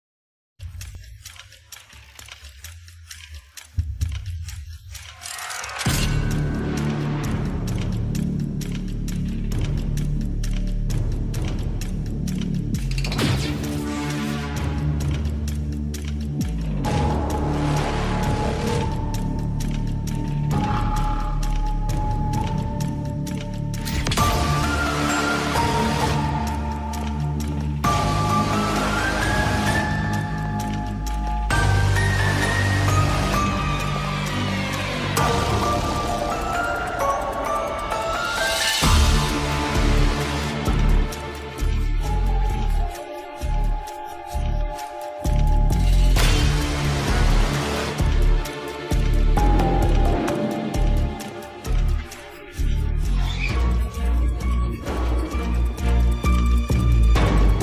soundtrack part one